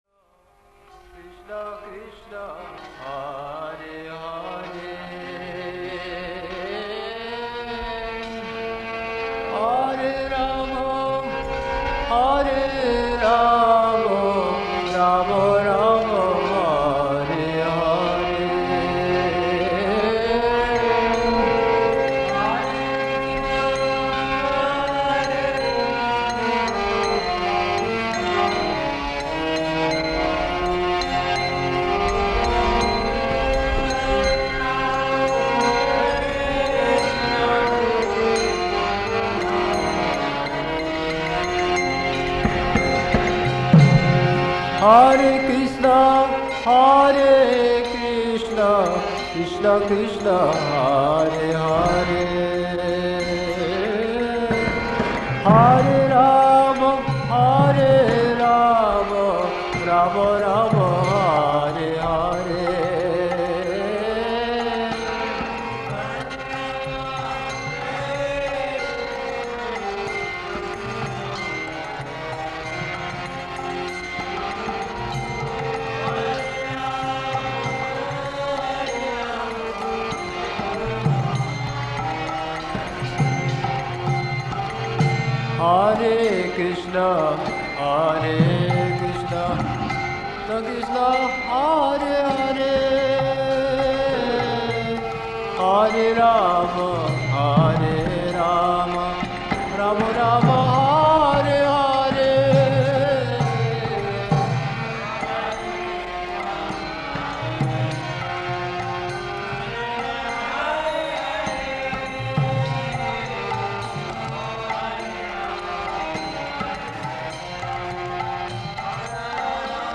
Hier noch eine exotische Mantrameditation zum Auftakt dieser bengalischen Adventszeit.